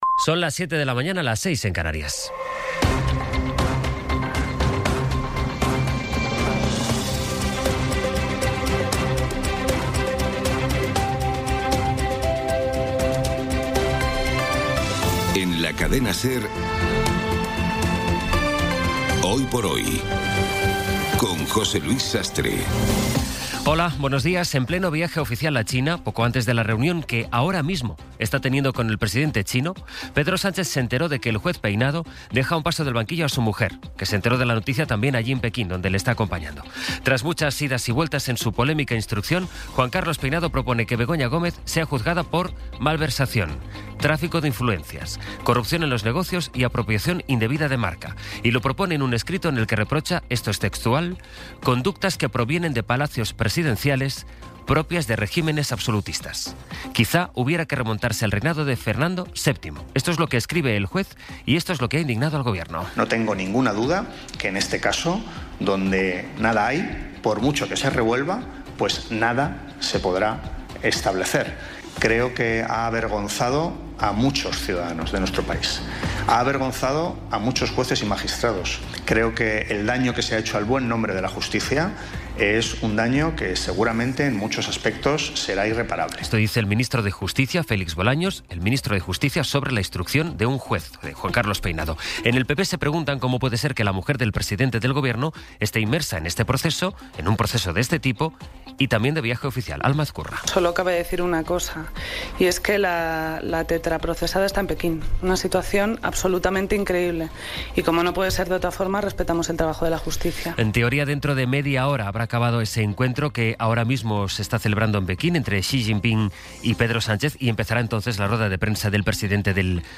Las noticias de las 07:00 20:05 SER Podcast Resumen informativo con las noticias más destacadas del 14 de abril de 2026 a las siete de la mañana.